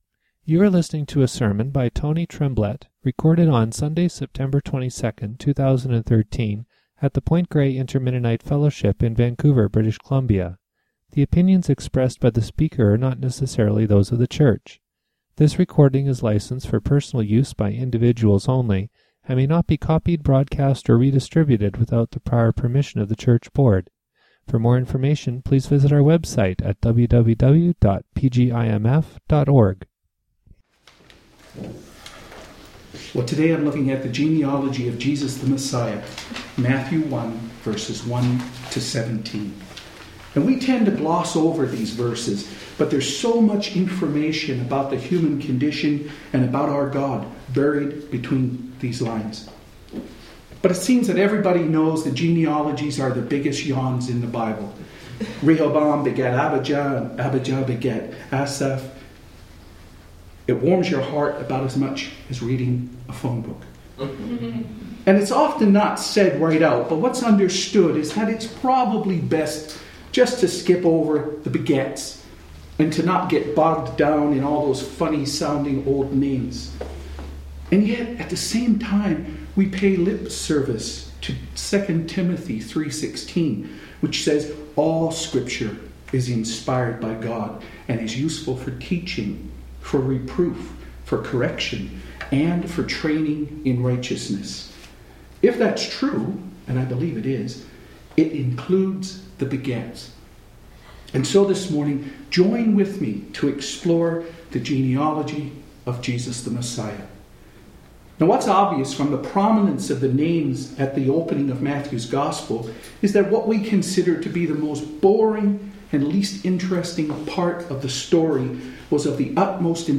Labels: PGIMF sermon discussion